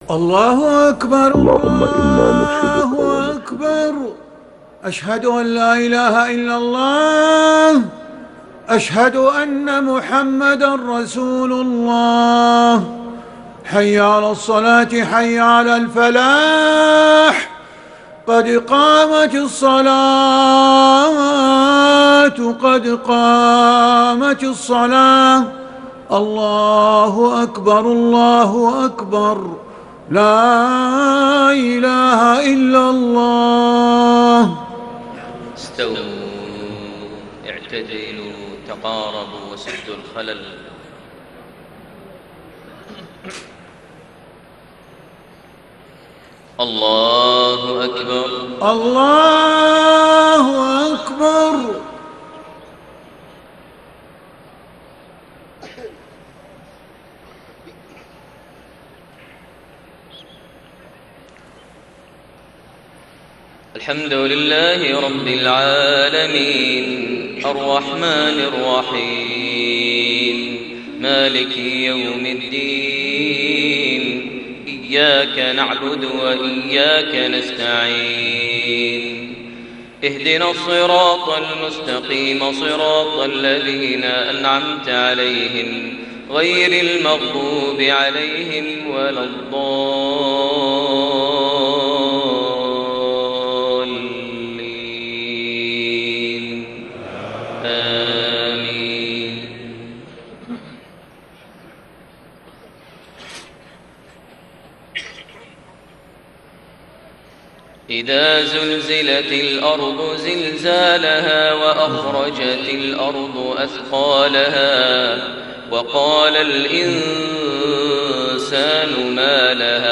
صلاة المغرب 3 شوال 1432هـ سورتي الزلزلة و القارعة > 1432 هـ > الفروض - تلاوات ماهر المعيقلي